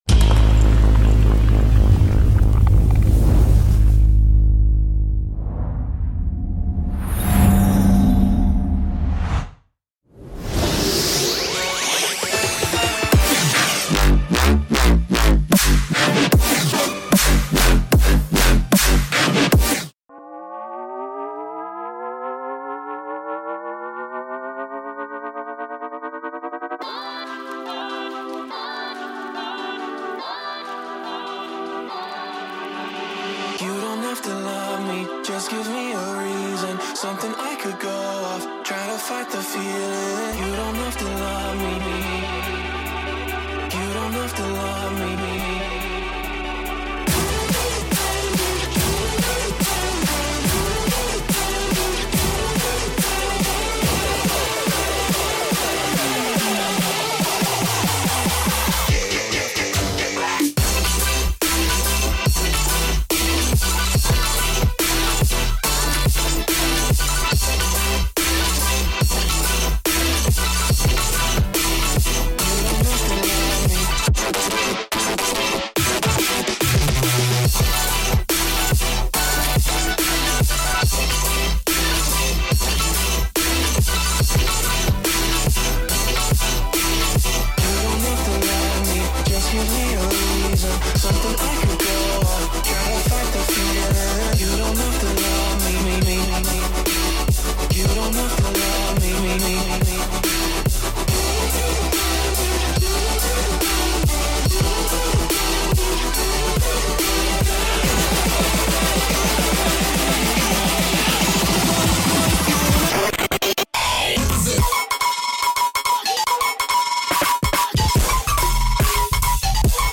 Genre: Dubstep